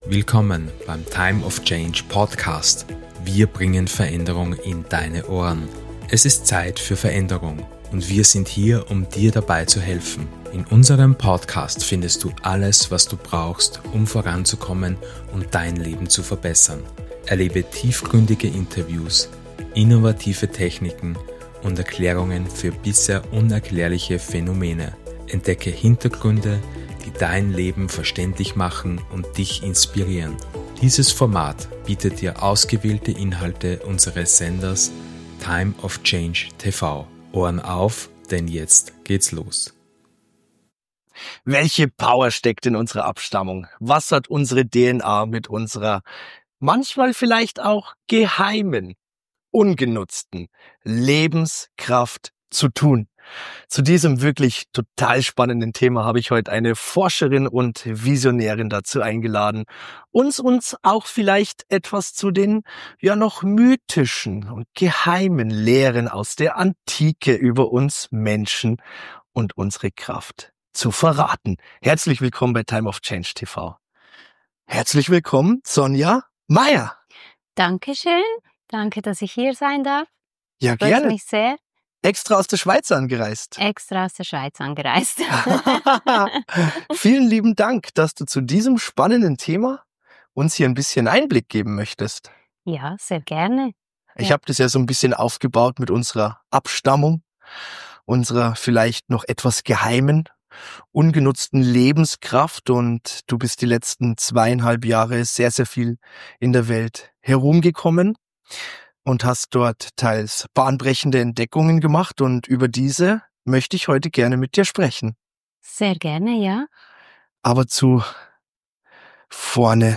In diesem Interview erfährst Du mehr über die Aktivierung unseres Potenzials, die Bedeutung von Energieheilung und die Rolle der Pyramiden als Energieportale.